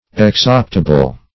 exoptable - definition of exoptable - synonyms, pronunciation, spelling from Free Dictionary
Exoptable \Ex*op"ta*ble\, a.